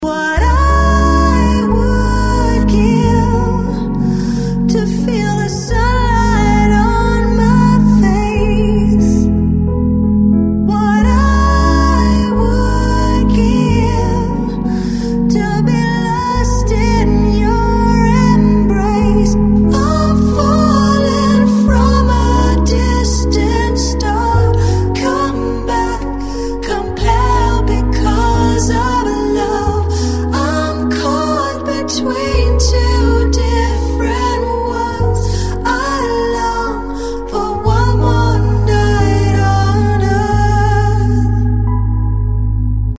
• Качество: 128, Stereo
спокойные